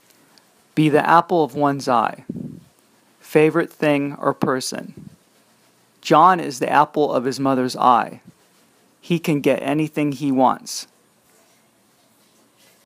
ネイティブによる英語音声をお聞きになるには、下記のURLをクリックしてください。